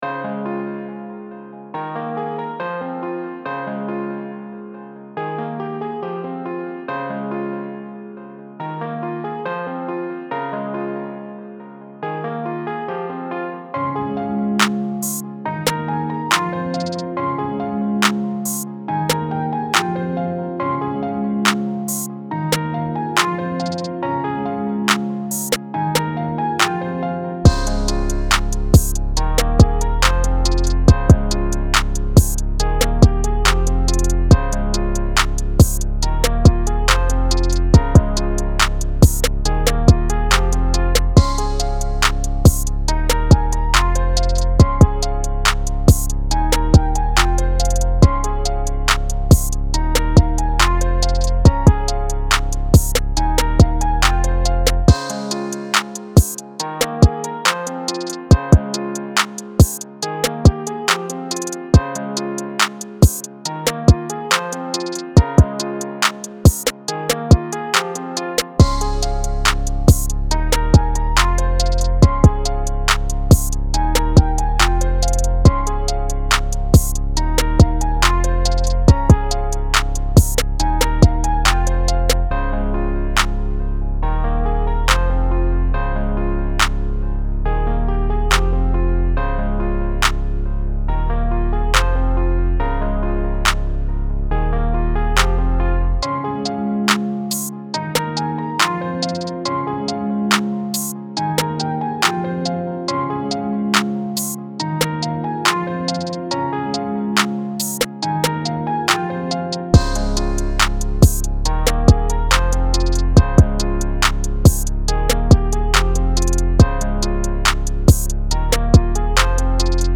90s, R&B
F Minor